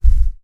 Вы можете слушать и скачивать мощные удары по стенам, грохот бронзового наконечника и другие эффекты, воссоздающие атмосферу средневековых битв.
Глухой звук удара от тарана